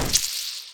Hit3.wav